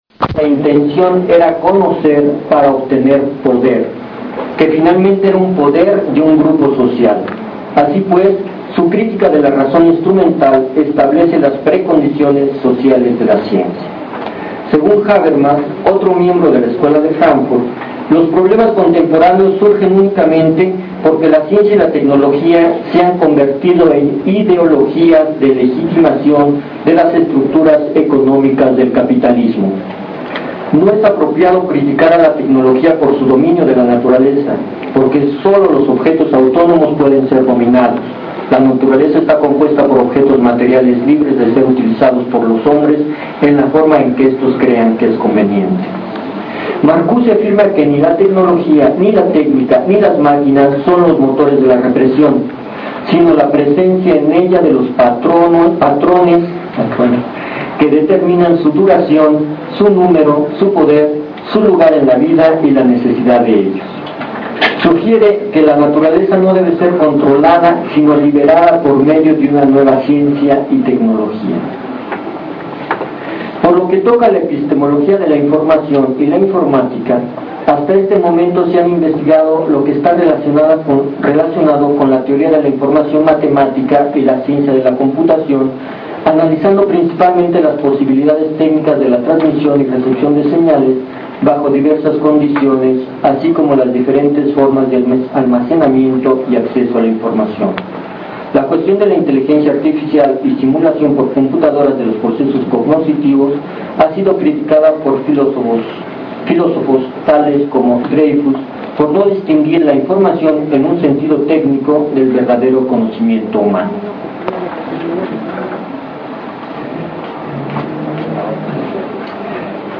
Lugar de la grabación: Biblioteca Central UNACH Fecha: Martes 14 de marzo de 2006.
Equipo: Grabadora analógica Sony (minicassette) Fecha: 2007-12-03 17:09:00 Regresar al índice principal | Acerca de Archivosonoro